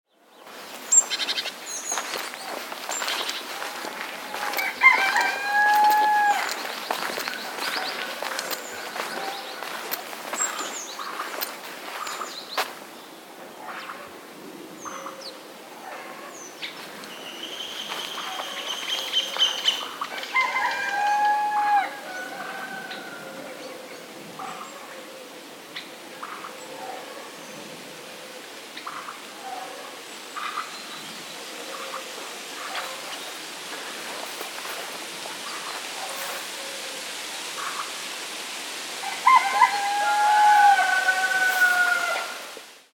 Village Morning Footsteps Sound Effect
A person walks on a gravel path in a quiet village early in the morning. You hear footsteps, birds singing, sheep, roosters crowing, and the wind blowing. Experience the peaceful rural ambience with authentic village sounds.
Village-morning-footsteps-sound-effect.mp3